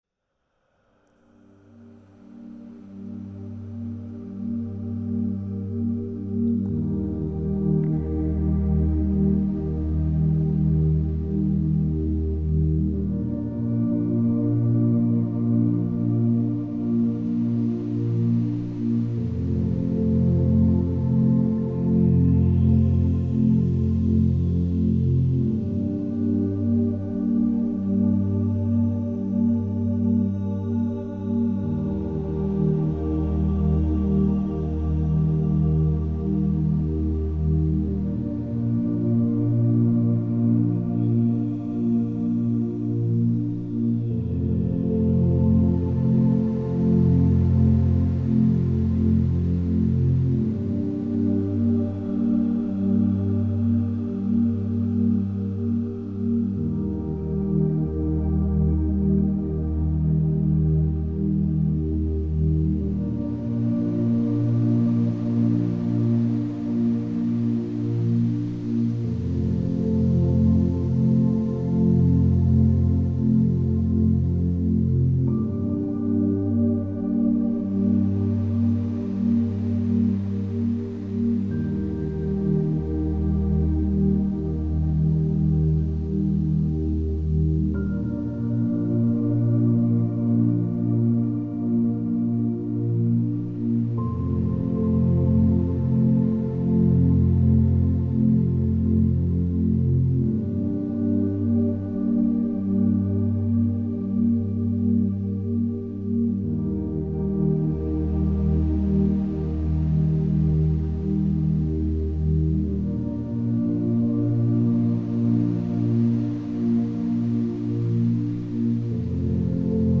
All advertisements are thoughtfully placed only at the beginning of each episode, ensuring you enjoy the complete ambient sounds journey without any interruptions. This commitment to your uninterrupted experience means no sudden advertising cuts will disturb your meditation, sleep, or relaxation sessions.